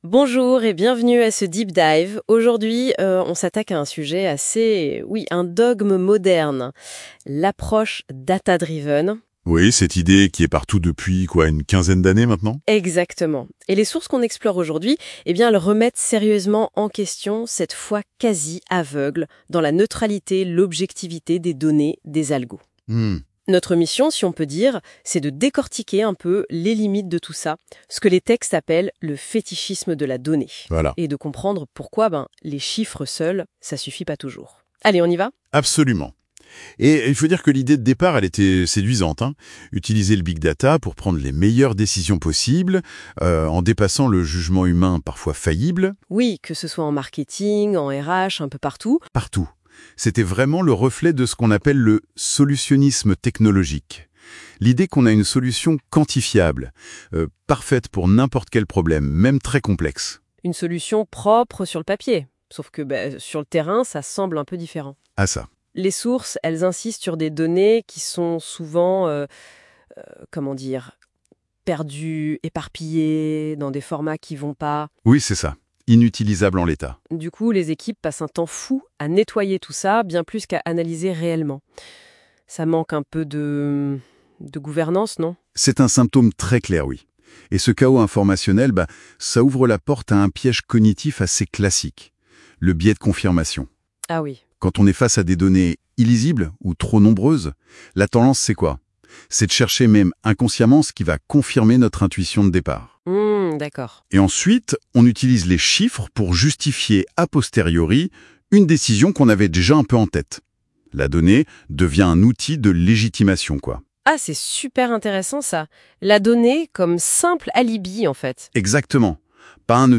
L'interview IA